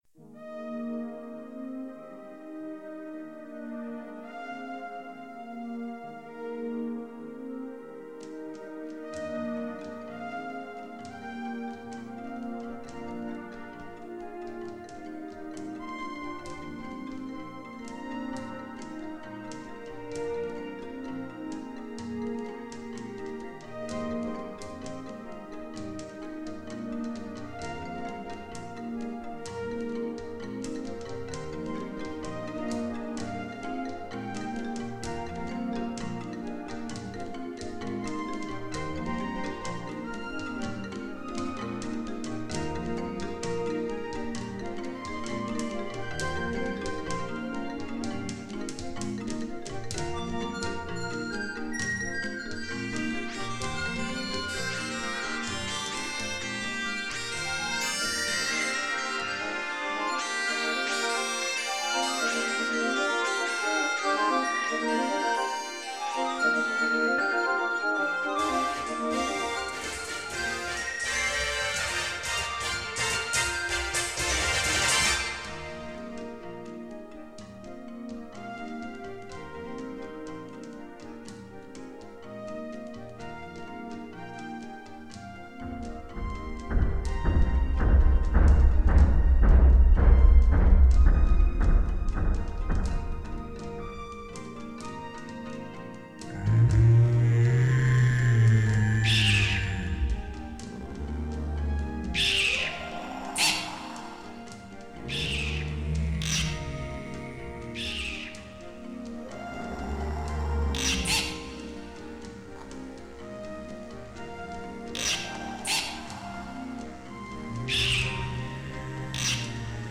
for Electronic music, Video, Laser, Performance and more
なお、残っていた音源がプラネタリウム公演用で、ダイナミクスの幅が広かったものは、若干のコンプ処理をしてあります。
この曲は、恐竜達の平和な営みが、その巨大隕石によって破壊されるシーンのために制作されたもので、後半は、その悲哀を描いたレクイエムなのかも知れません。